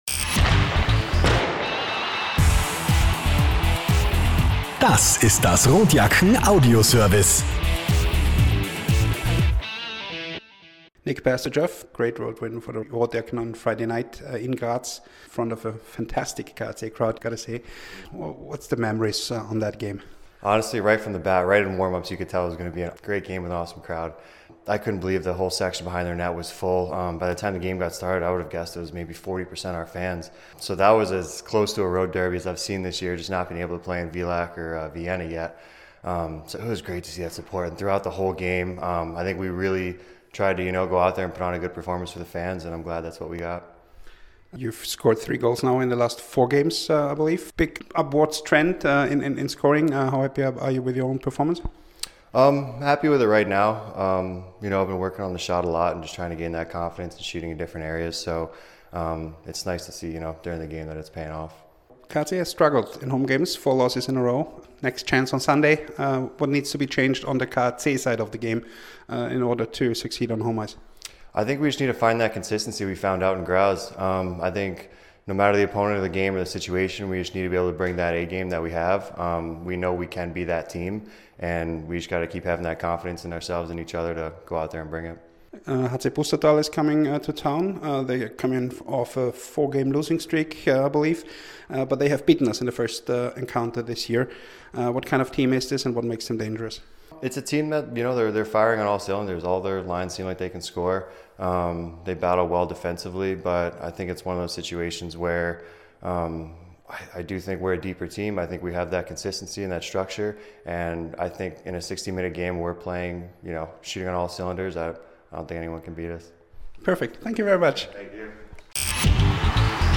Pre-Game-Kommentar: